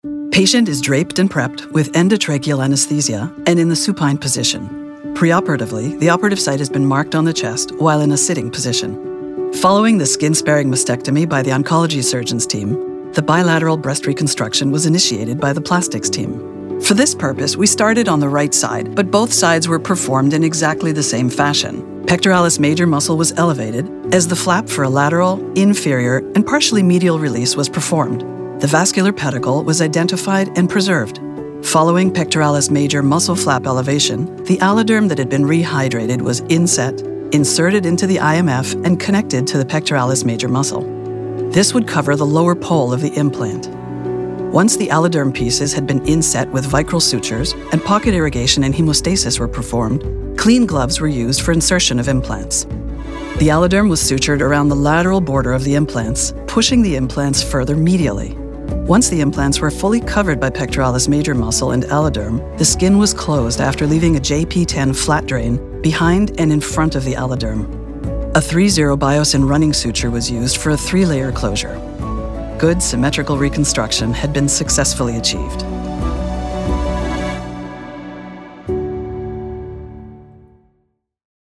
Montreal English / Canadian English
Velvety smooth, luxurious contralto.
Paired with a delivery that is professional and authoritative, the Doctor’s voice is sophisticated, clear, smooth and articulate.